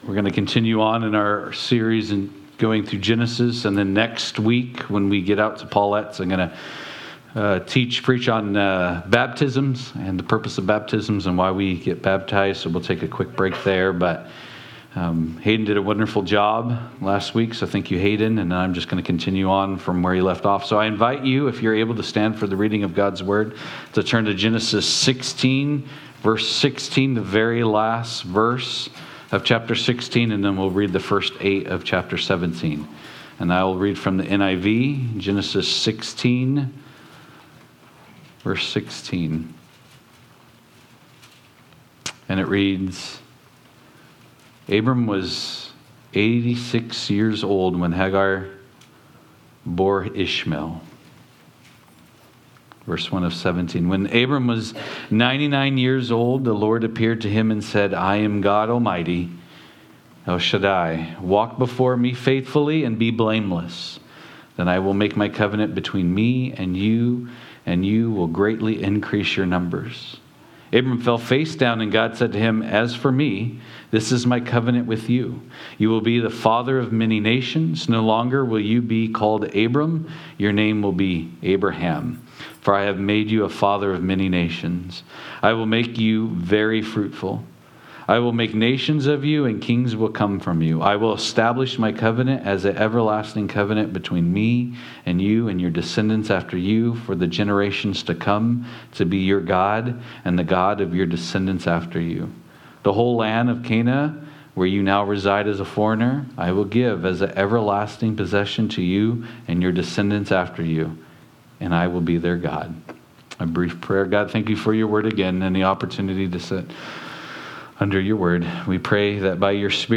Genesis Service Type: Sunday Morning « Genesis-In the Beginning